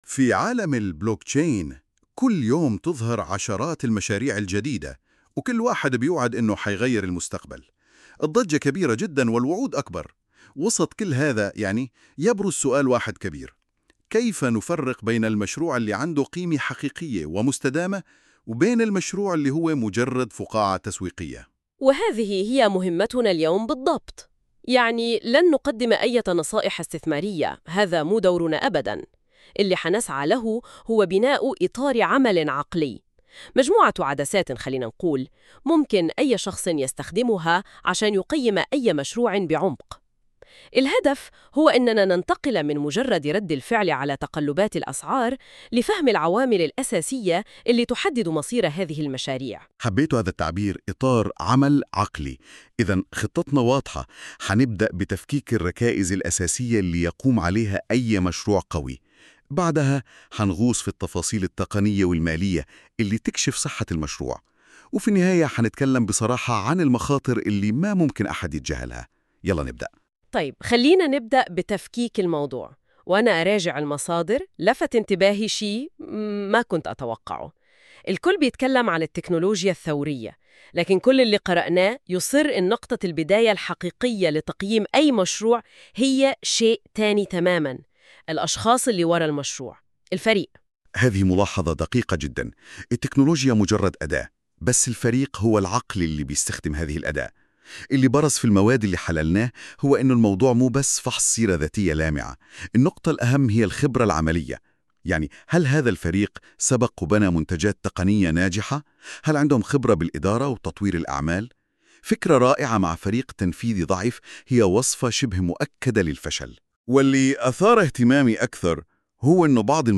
👇 يمكنك الاستماع إلى هذا المقال بدلاً من قراءته عبر المقطع الصوتي التالي